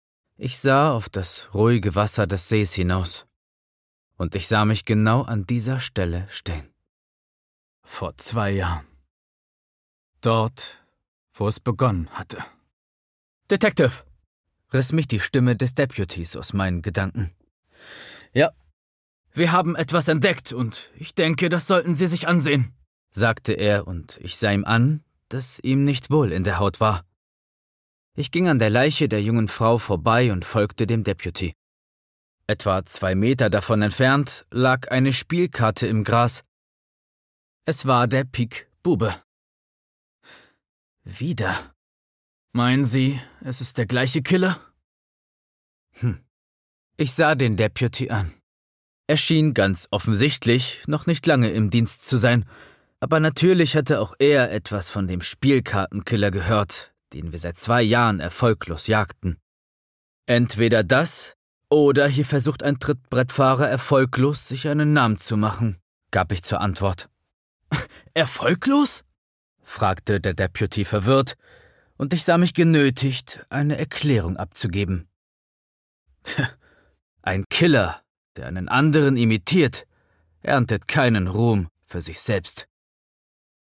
Gezeichneter Detektiv mit dunkler Vergangenheit auf der Suche nach der Wahrheit. Hörbuch, Crime